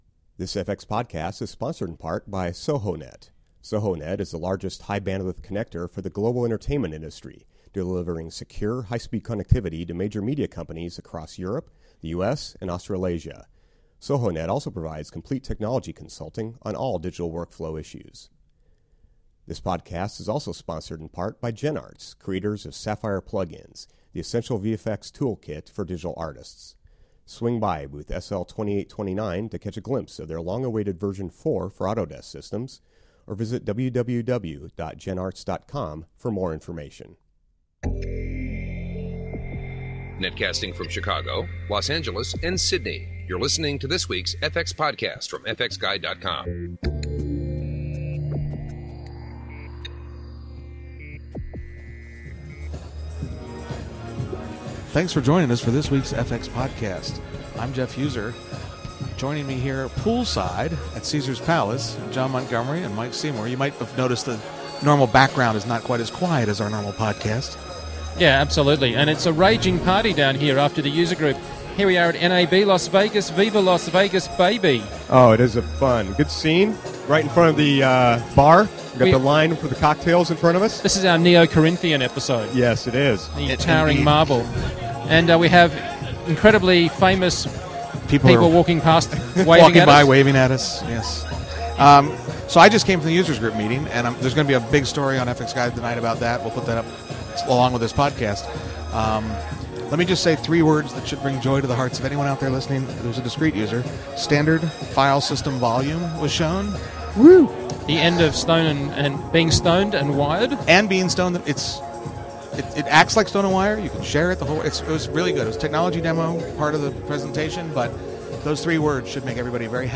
Wrap up of Sunday's user group and our first NAB 2007 podcast, a "Live" podcast from the Autodesk Pool Party at Caesar's Palace.
We’ll cover the highlights in this article and then in the podcast we talk with several members of the Autodesk team.